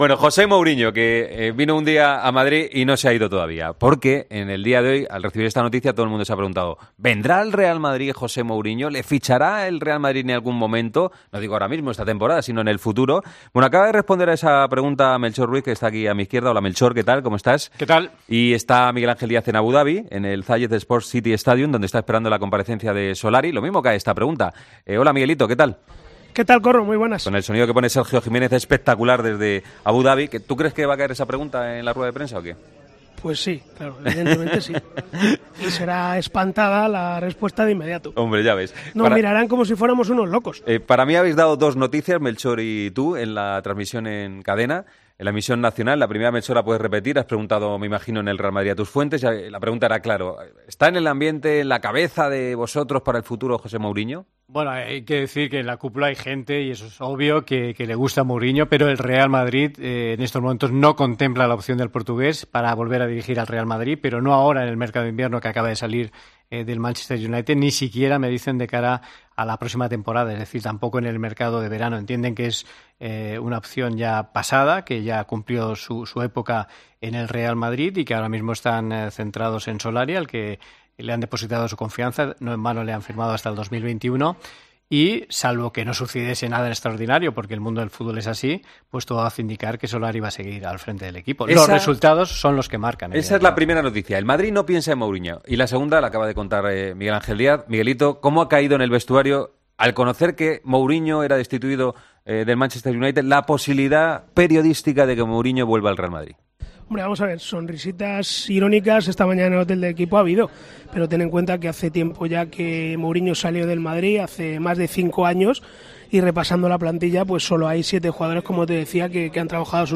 Noticia COPE